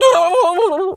turkey_ostrich_hurt_gobble_10.wav